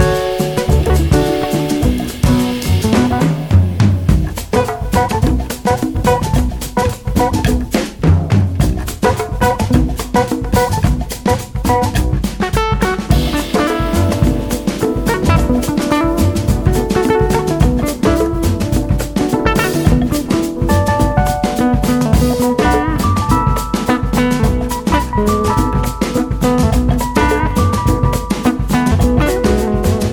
0 => "Jazz"